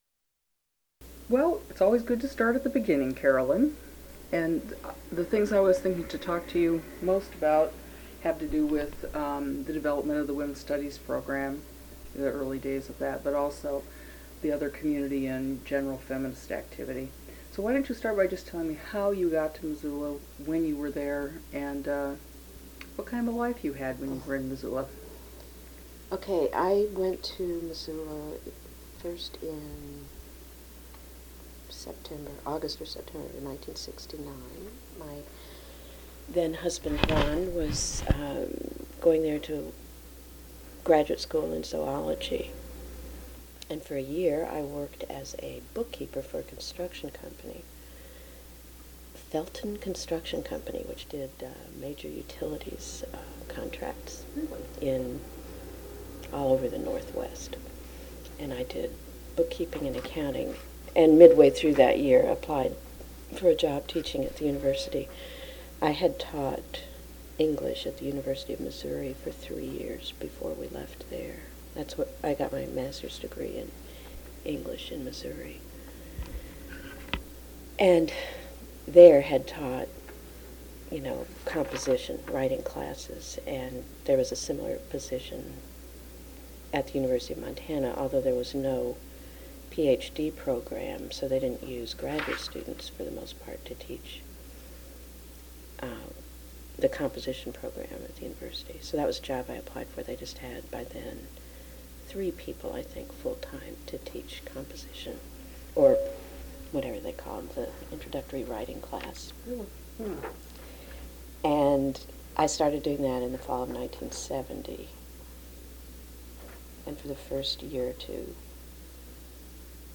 Oral History
1 sound cassette (00:60:00 min.): analog